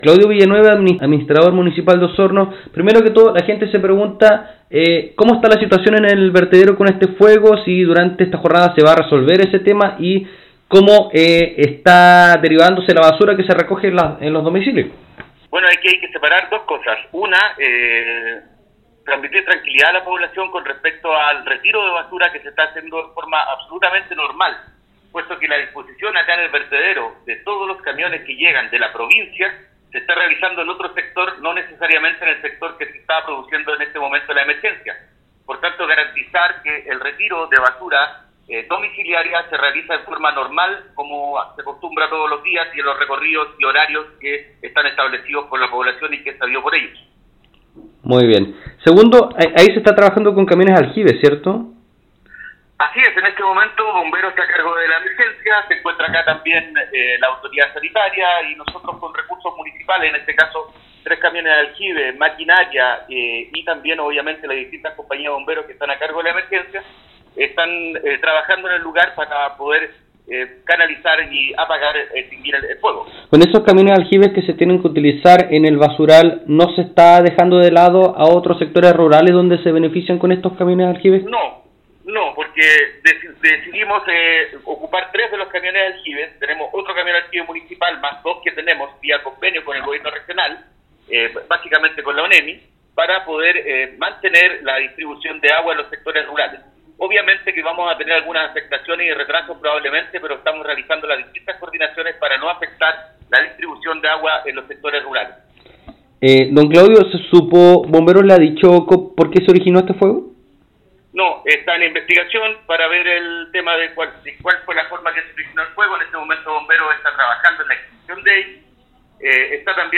Fue el alcalde (s) de Osorno, Claudio Villanueva, quien entregó nuevos antecedentes respecto al fuego que afecta a esta hora al Vertedero Curaco.
ENTREVISTA.mp3